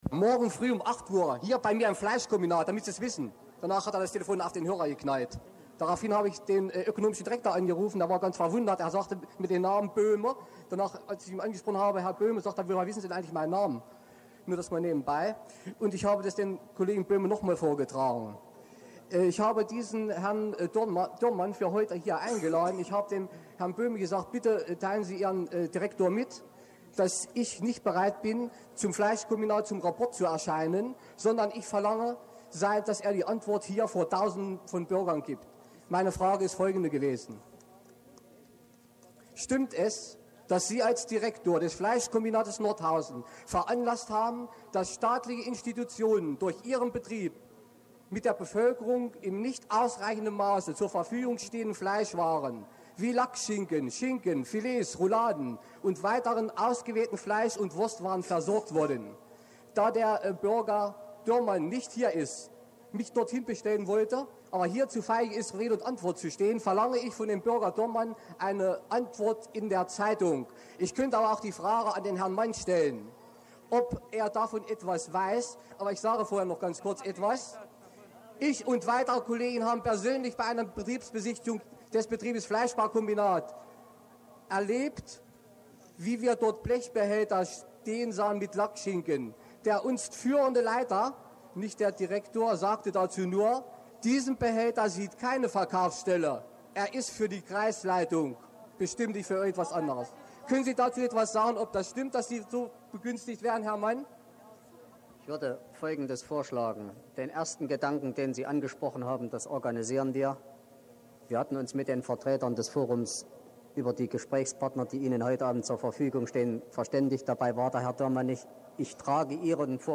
Demo auf dem August-Bebel-Platz am 7. November 1989